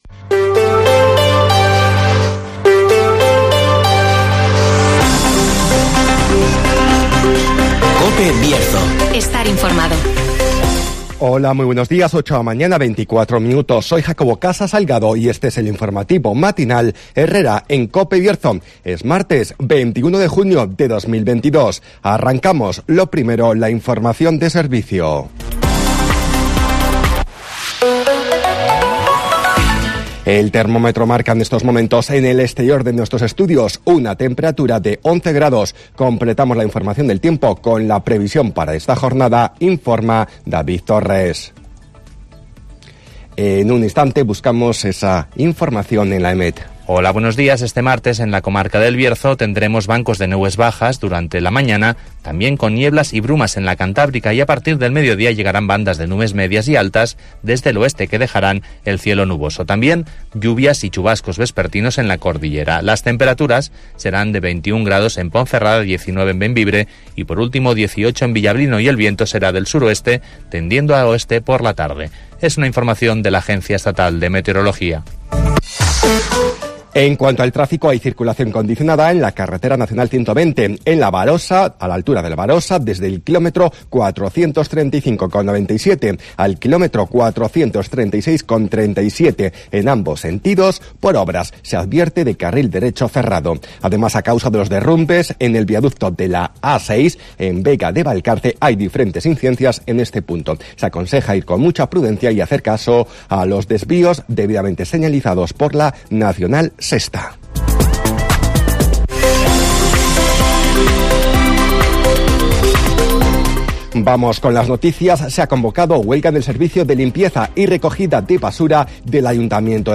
INFORMATIVOS